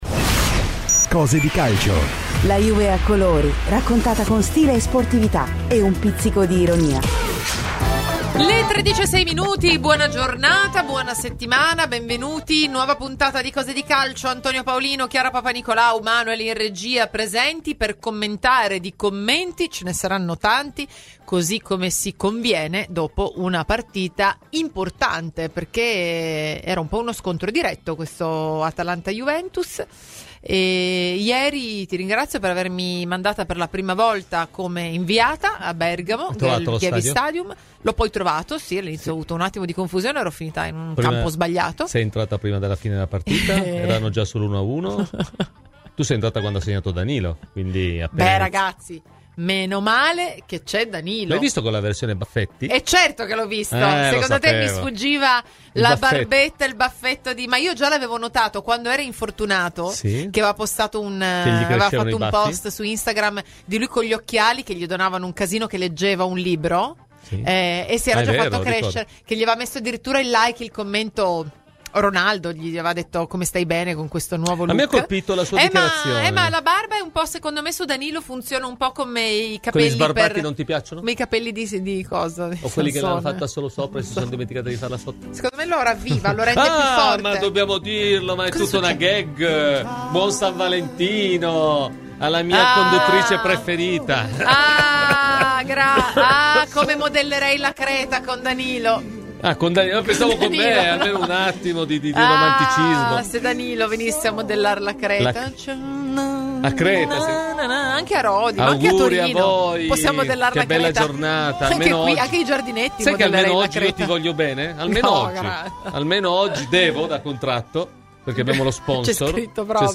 Quindi, il focus dell'intervista si basa sulle decisioni arbitrali che più hanno fatto discutere in relazione alla partita del Gewiss Stadium, in cui l'Atalanta ha recriminato soprattutto per il contatto tra Szczęsny e Muriel .